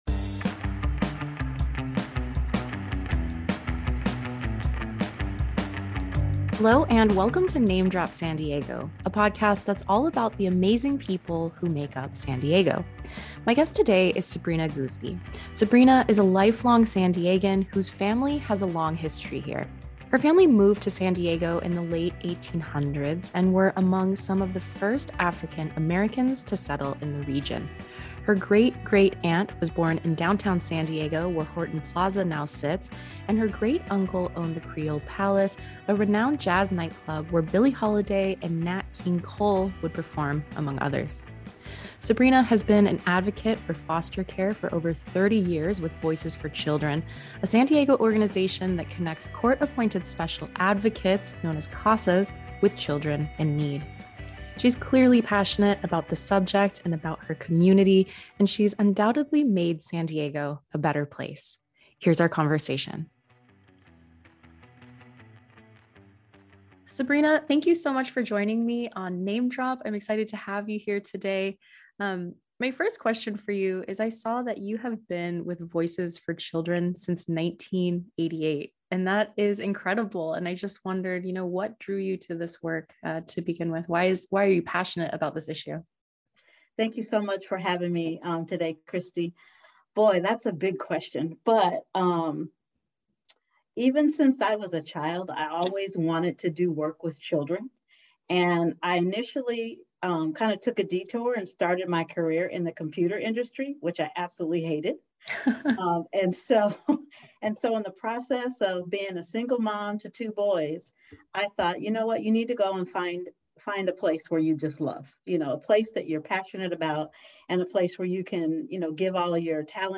News Talk
Celebrity Interviews